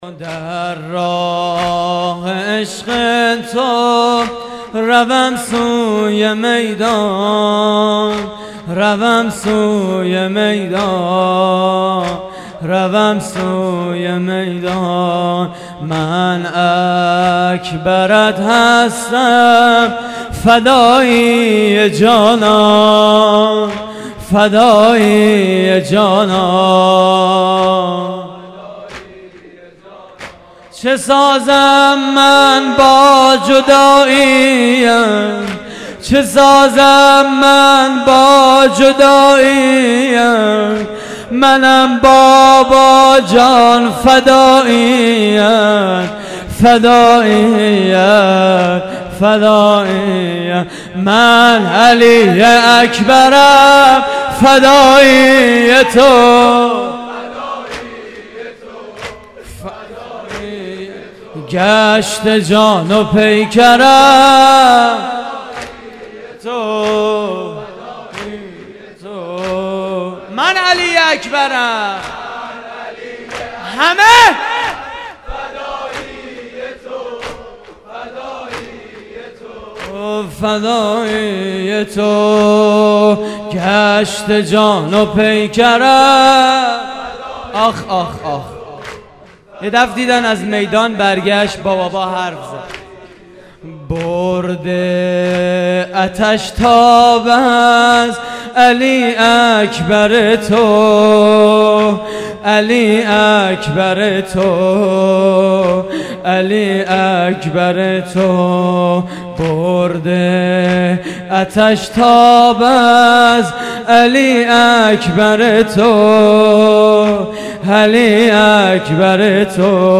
مراسم عزاداری شب هشتم ماه محرم